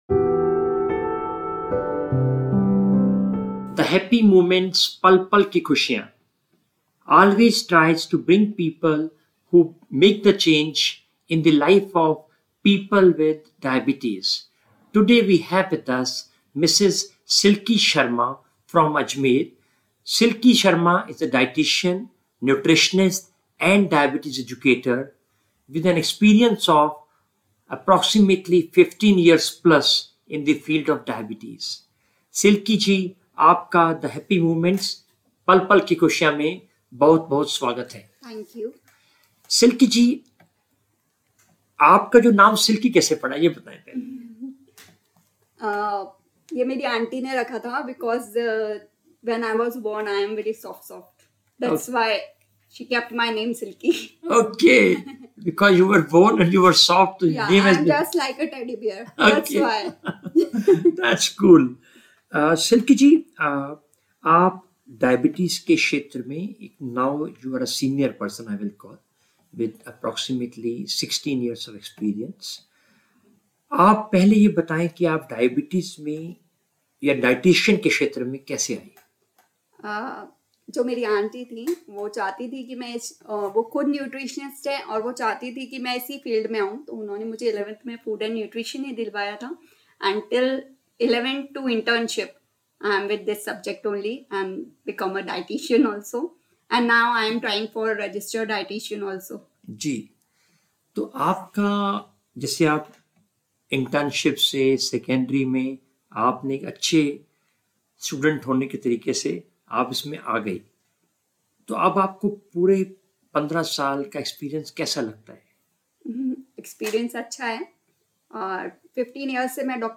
an inspiring conversation